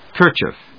音節ker・chief 発音記号・読み方
/kˈɚːtʃɪf(米国英語), kˈəːtʃɪf(英国英語)/